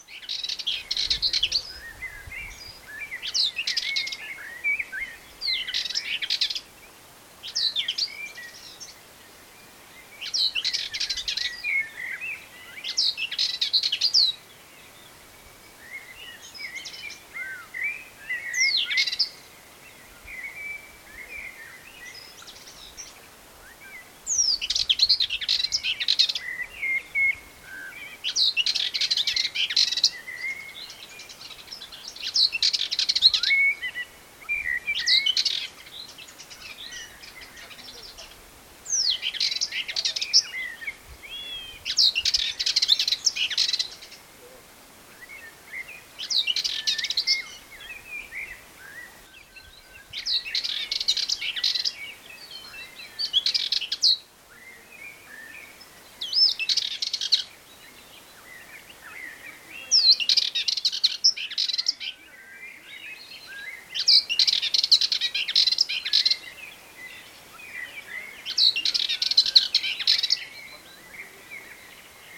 Data resource Xeno-canto - Bird sounds from around the world